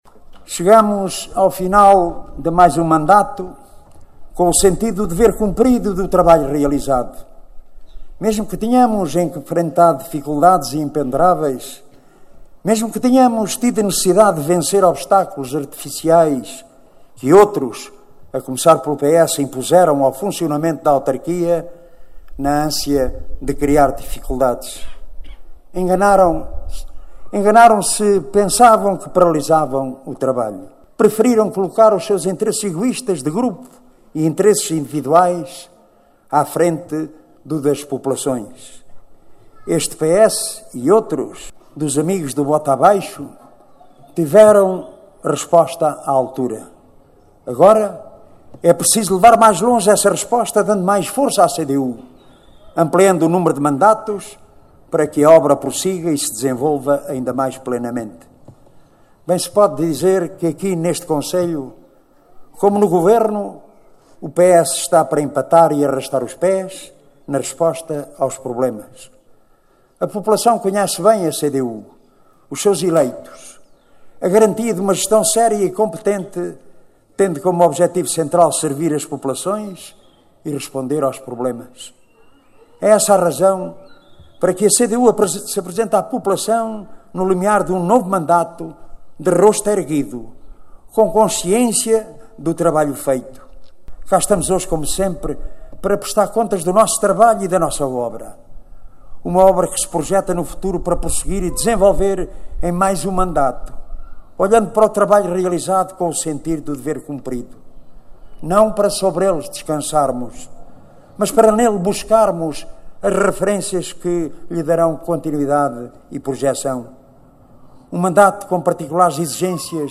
O secretário-geral do PCP Jerónimo de Sousa, esteve no passado sábado, na Vidigueira, numa sessão pública de apoio à recandidatura de Rui Raposo, à Câmara Municipal de Vidigueira.